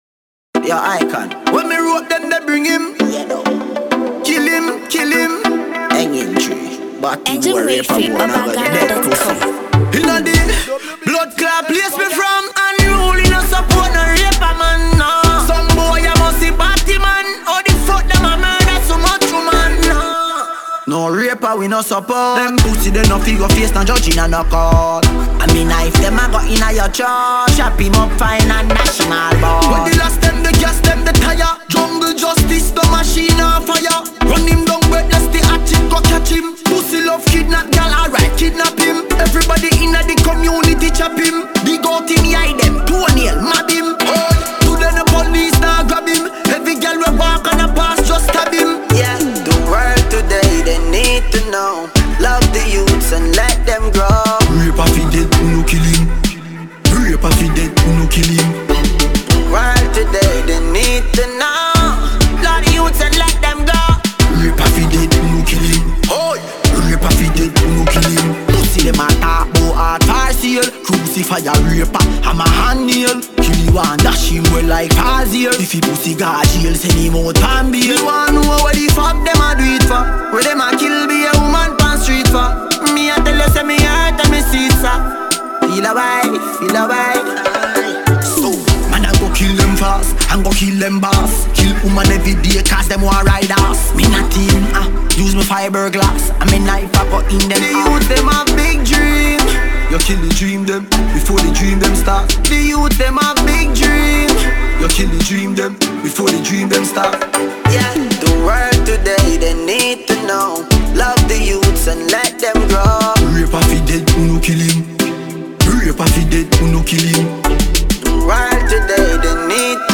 Reggae/Dancehall
This is real dancehall vibes!!